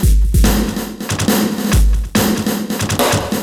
E Kit 01.wav